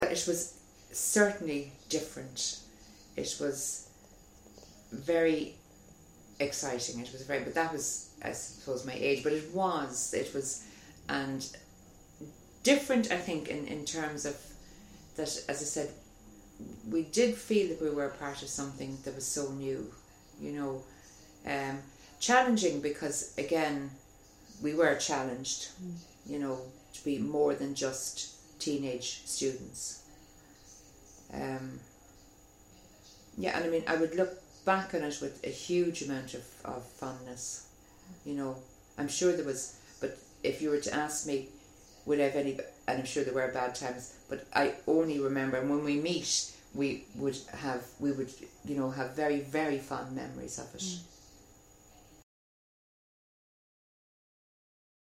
University of Limerick oral history
Interviewer (ivr)
Interviewee (ive)